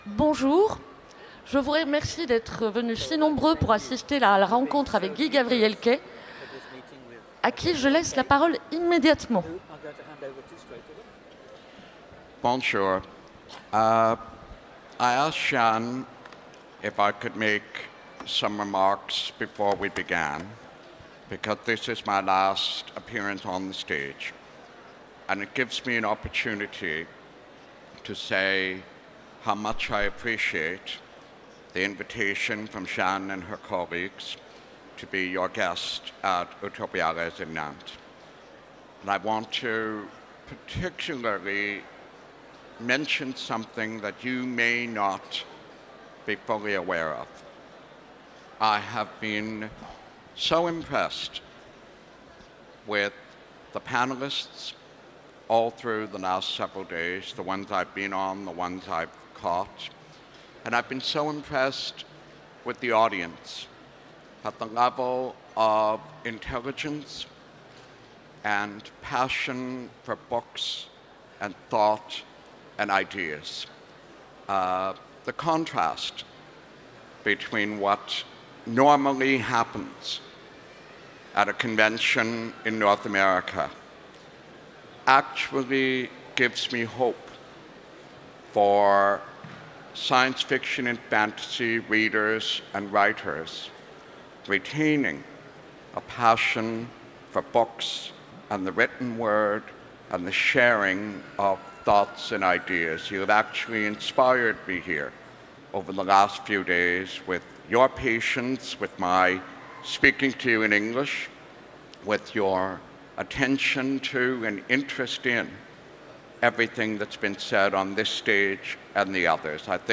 Utopiales 2017 : Rencontre avec Guy Gavriel Kay
- le 20/11/2017 Partager Commenter Utopiales 2017 : Rencontre avec Guy Gavriel Kay Télécharger le MP3 à lire aussi Guy Gavriel Kay Genres / Mots-clés Rencontre avec un auteur Conférence Partager cet article